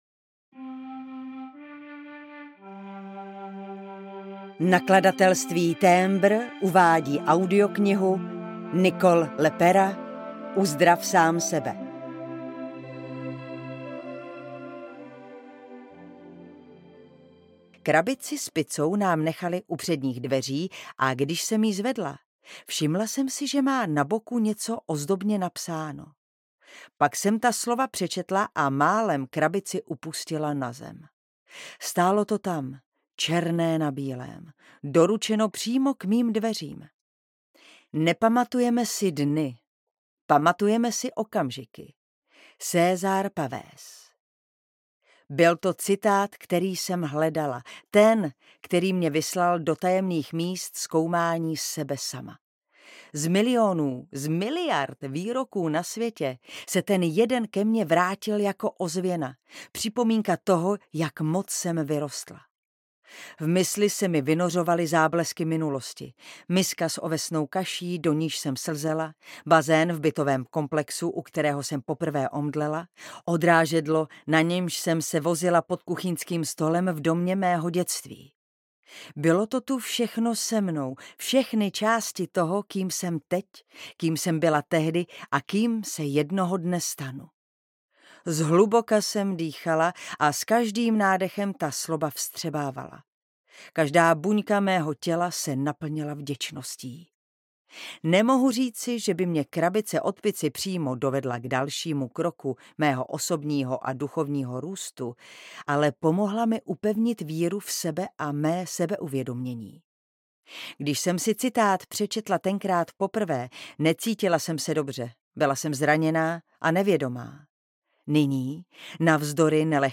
Uzdrav sám sebe audiokniha
Ukázka z knihy